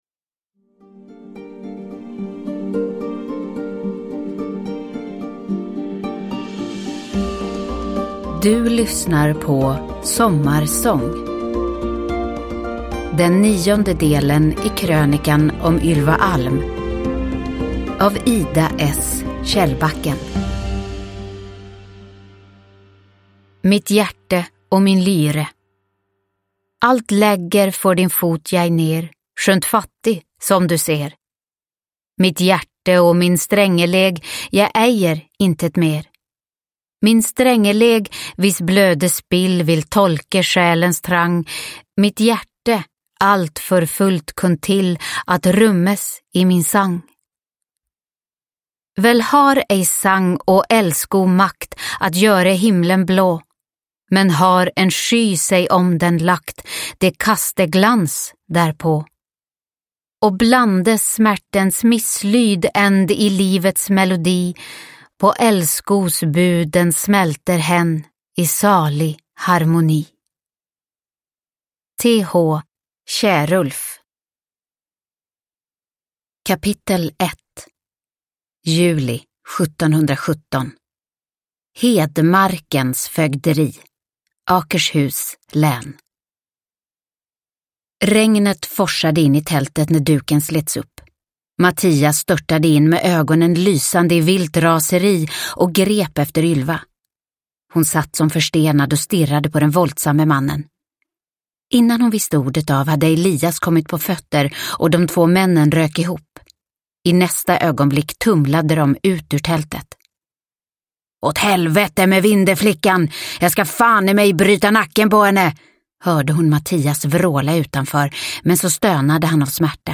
Sommarsång – Ljudbok – Laddas ner